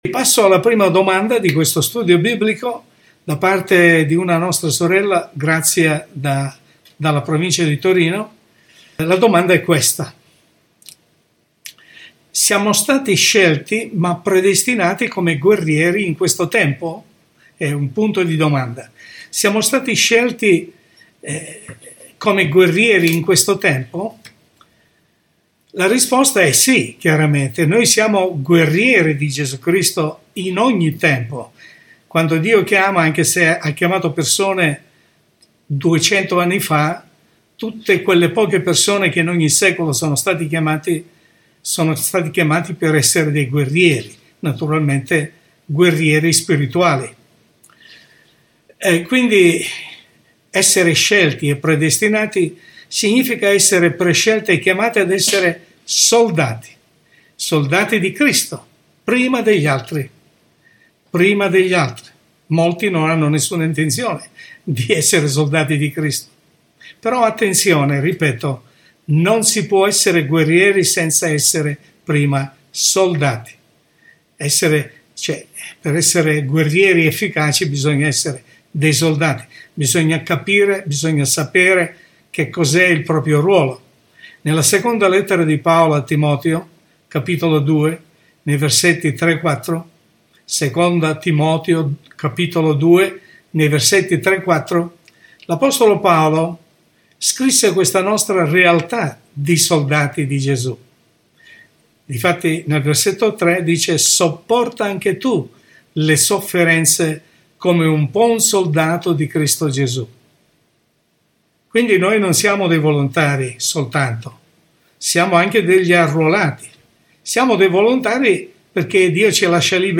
Studio Biblico